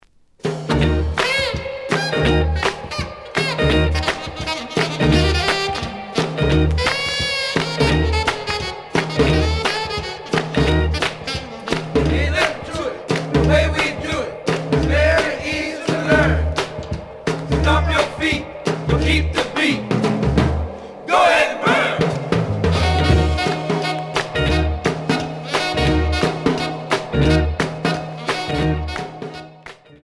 The audio sample is recorded from the actual item.
●Genre: Rhythm And Blues / Rock 'n' Roll
Some click noise on some parts of B side, but almost good.